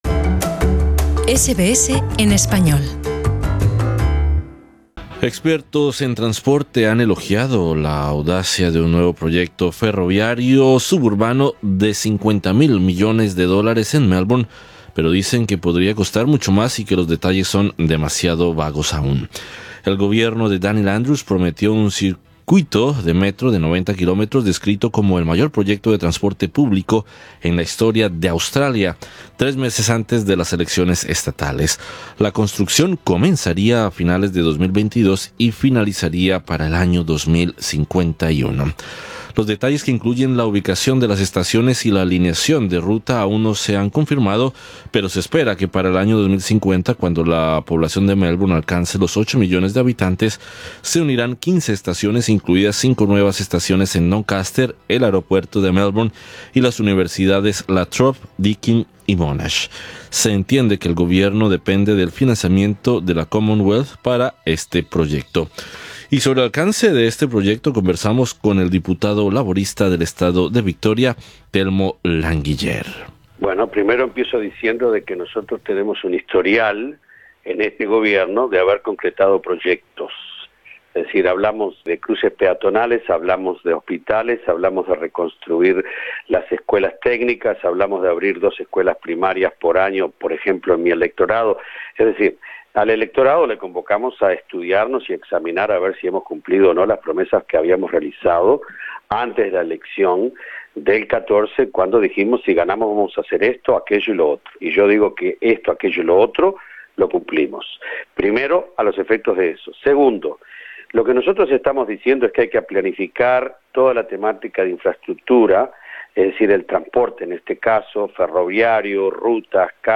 Sobre el alcance de este proyecto conversamos con el diputado laborista del estado de Victoria, Telmo Languiller.